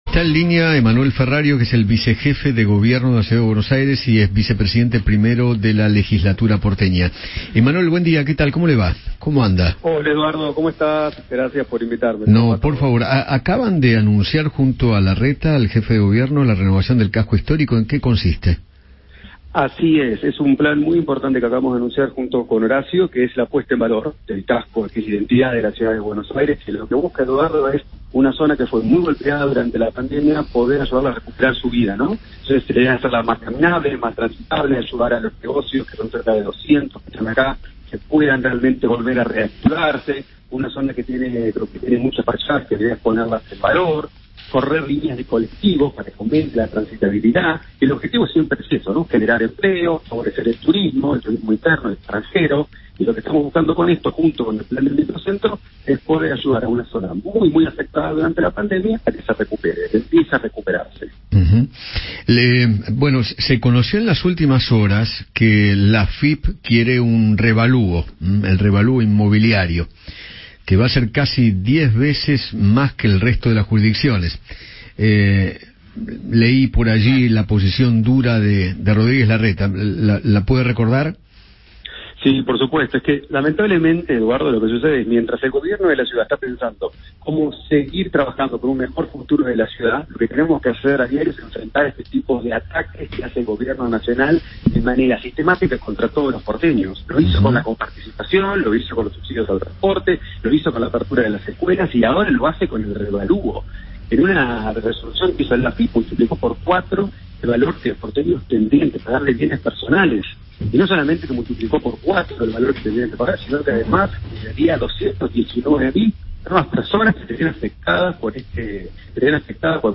El vicepresidente primero de la Legislatura porteña, Emmanuel Ferrario, conversó con Eduardo Feinmann sobre la candidatura de Javier Milei a presidente 2023. Además, habló acerca de la puesta en valor del casco histórico de la Ciudad.